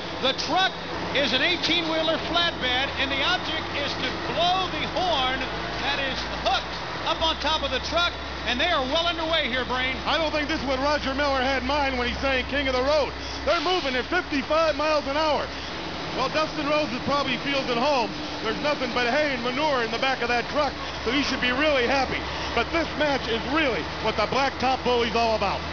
Let’s let Tony Schiavone and Bobby Heenan tell you all about this exciting contest.